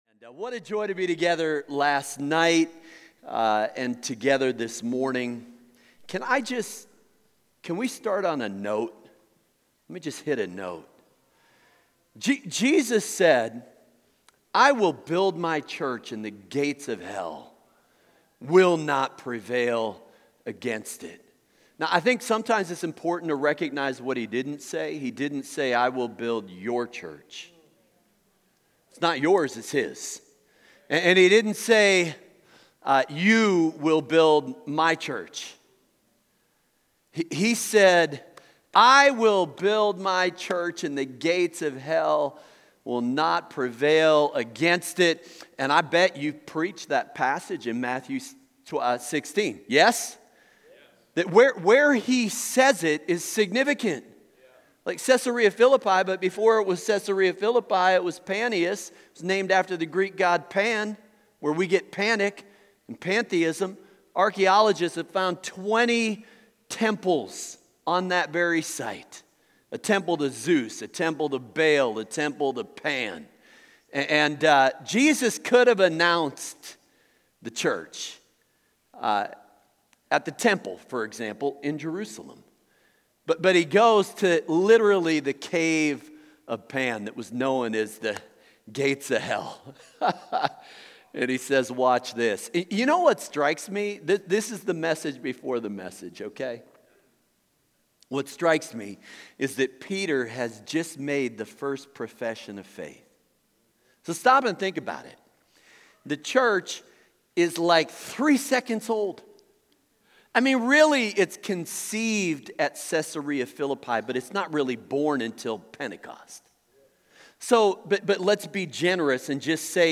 Message
Oct. 1 at 9:00 am at Prayer & Fasting Retreat